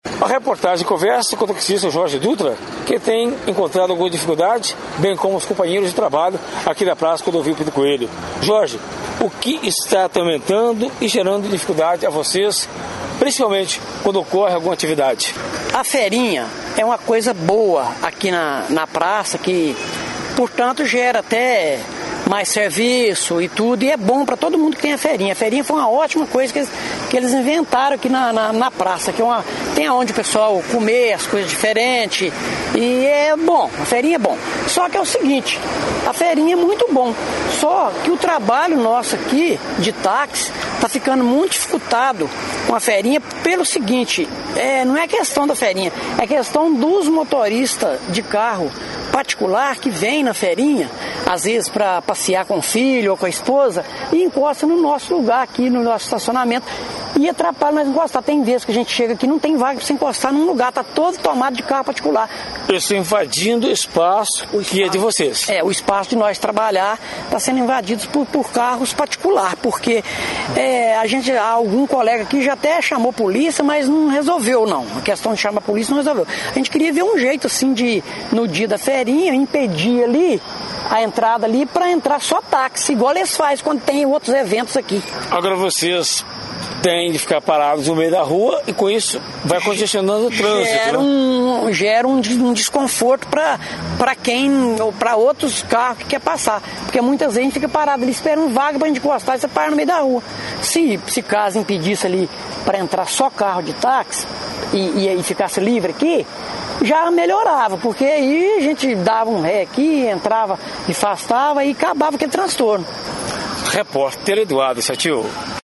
Ouça a entrevista completa com o taxista: